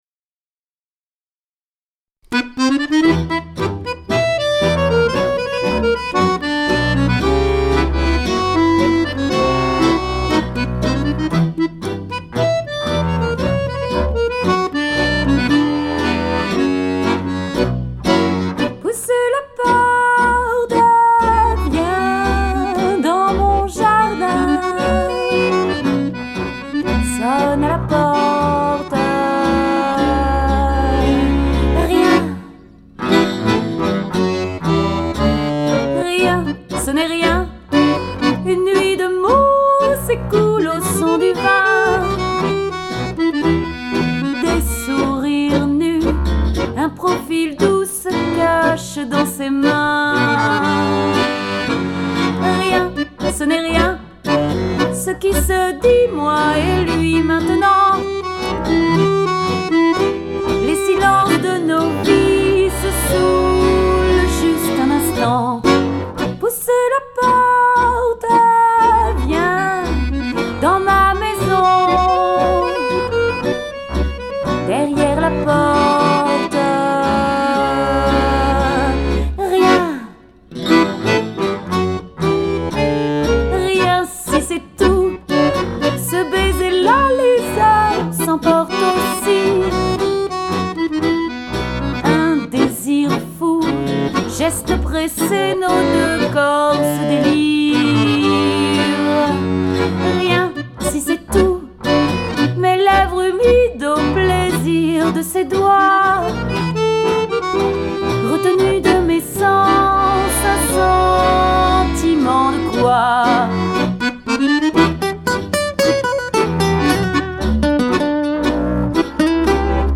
LES CHANSONS TANGOS